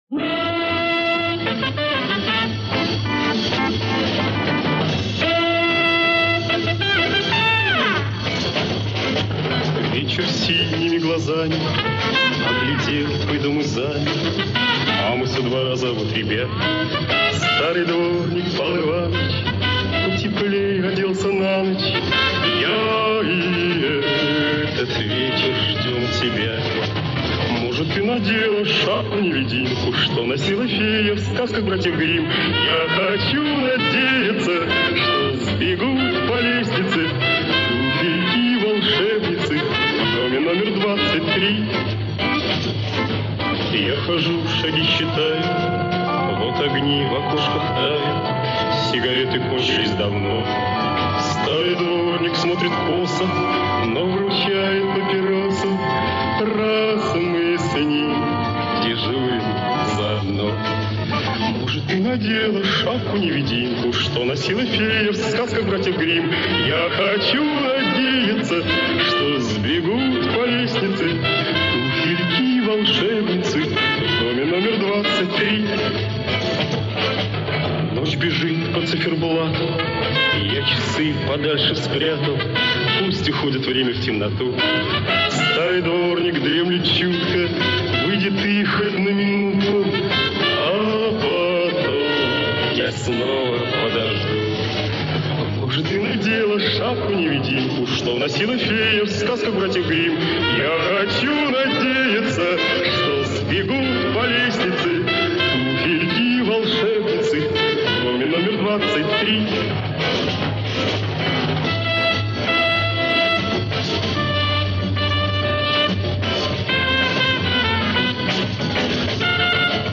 подрихтовал микроямки и почистил шум ленты.